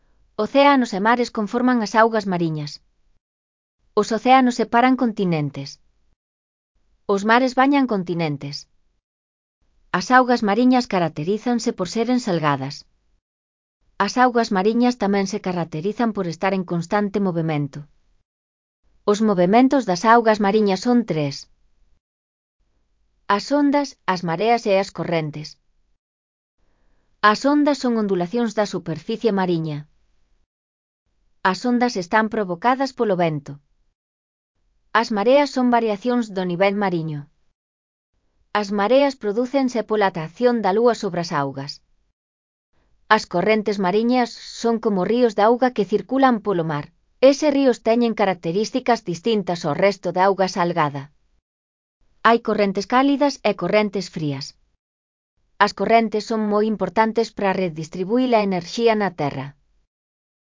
Lectura facilitada